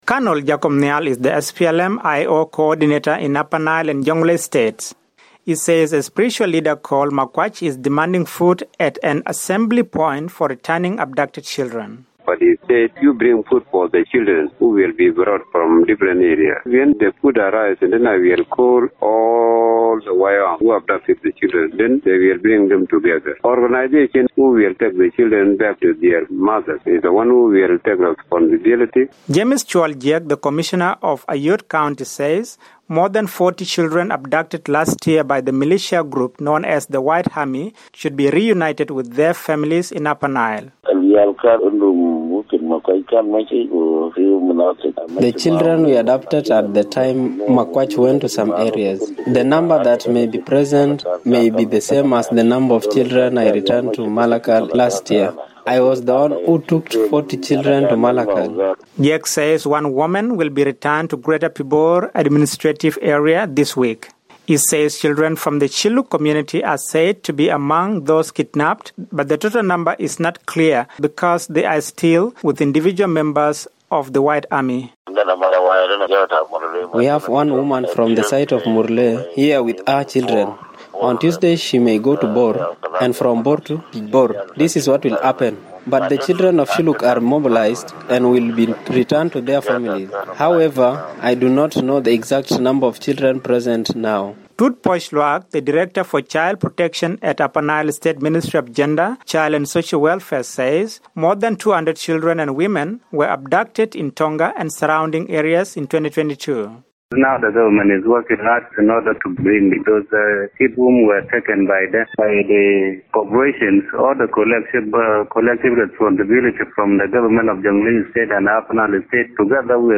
reports for VOA from Malakal.